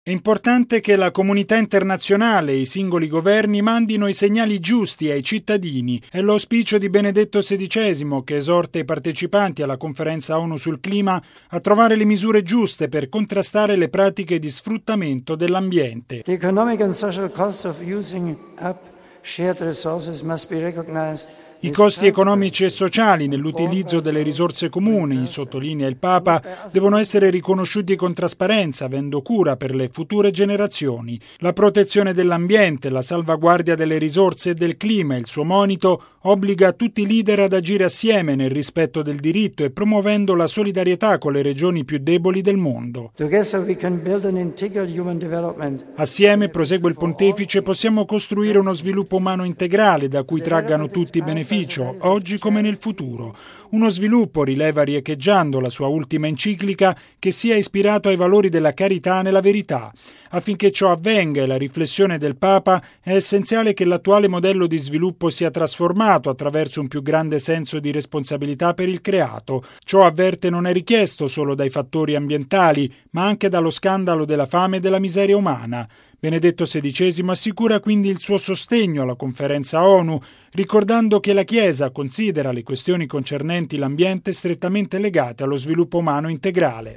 ◊   I leader degli Stati agiscano con coraggio per salvaguardare il dono prezioso del Creato: è l’esortazione di Benedetto XVI contenuta in un videomessaggio rivolto ai partecipanti al Summit dell’Onu sui cambiamenti climatici, a New York. Il testo del messaggio riproduce quanto il Papa ha detto durante l’udienza generale del 26 agosto scorso, nella quale ha dedicato ampio spazio al tema della difesa dell’ambiente.